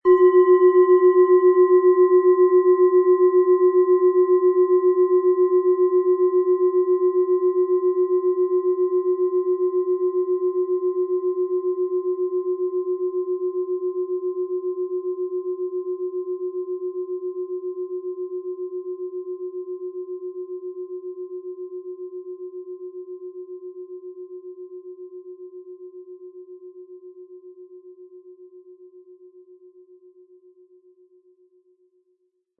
Planetenschale® Lebensfreude wahrnehmen & Erkenne Deinen Lebensweg mit Jupiter, Ø 15,4 cm, 700-800 Gramm inkl. Klöppel
Planetenton 1
Sie möchten den schönen Klang dieser Schale hören? Spielen Sie bitte den Originalklang im Sound-Player - Jetzt reinhören ab.
Lieferung mit richtigem Schlägel, er lässt die Klangschale harmonisch und wohltuend schwingen.
MaterialBronze